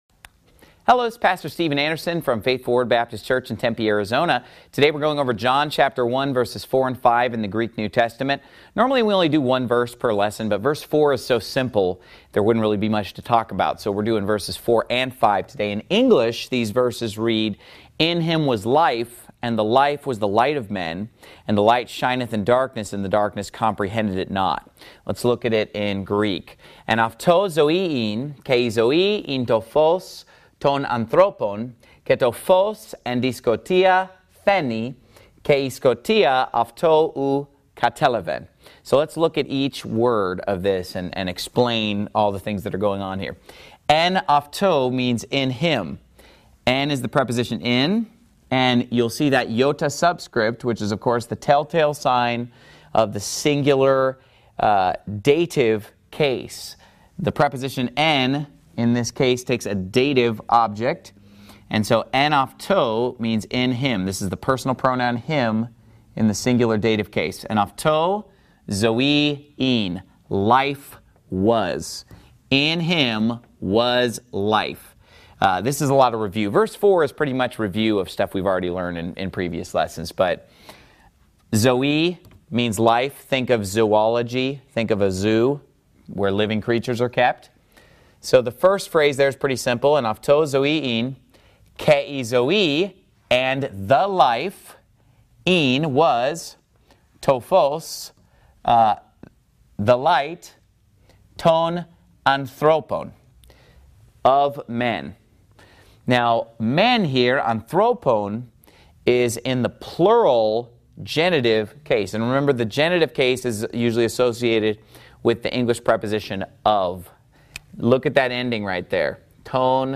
Greek 22 New Greek Lesson With New Testament John 1 4-5
Greek_22_New_Greek_Lesson_With_New_Testament_John_1_4-5.mp3